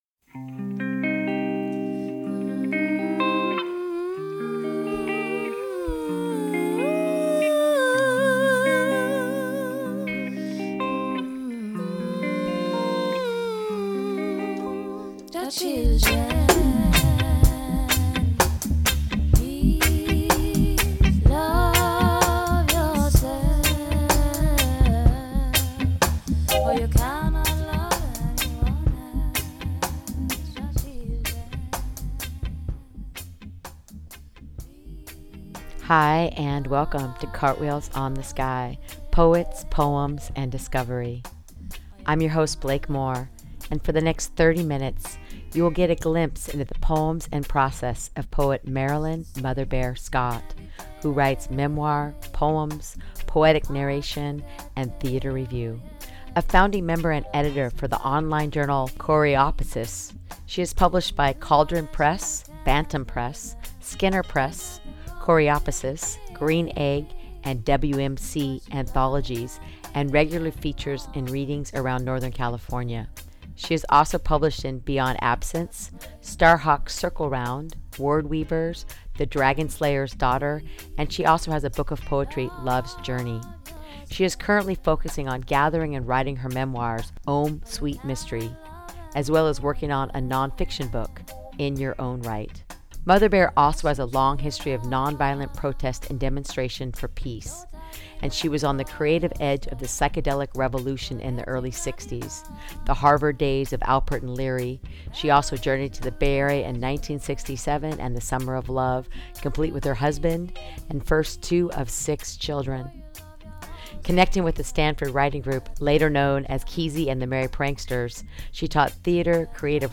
Highlighting a new poet in conversation each week, the show offers fresh, intelligent, living poetry and lively discussions.